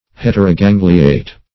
Meaning of heterogangliate. heterogangliate synonyms, pronunciation, spelling and more from Free Dictionary.
Search Result for " heterogangliate" : The Collaborative International Dictionary of English v.0.48: Heterogangliate \Het`er*o*gan"gli*ate\, a. [Hetero- + gangliate.]